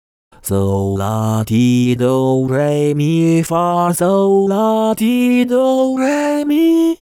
◯英語音源（English）
【説明】 ： E4以上が裏声になります。CVVCの音源です。
音階は g2, a#2, c#3, e3, g3, a#3, c#4, e4の８つです。